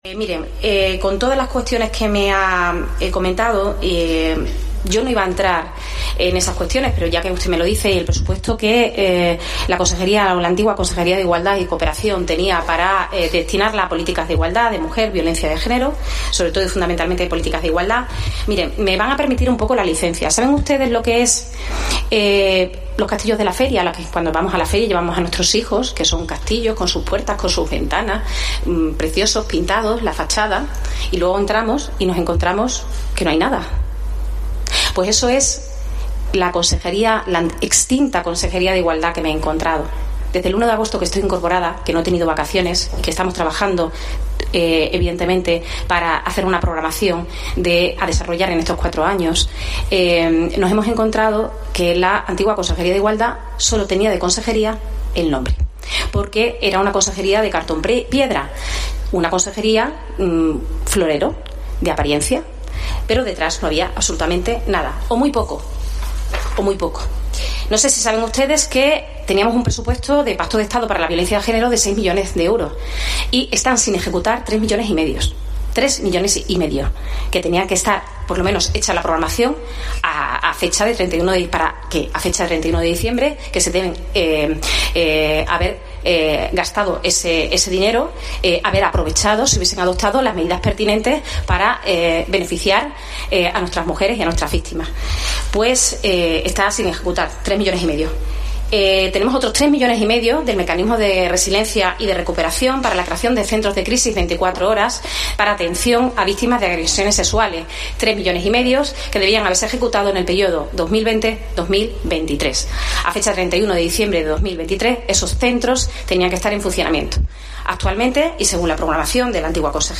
"Sólo tenía de consejería el nombre, era una consejería de cartón-piedra, florero, donde detrás había muy poco", ha dicho este miércoles la secretaria general de Igualdad y Conciliación, Ara Sánchez, en la Comisión de Presidencia, Interior y Diálogo Social de la Asamblea.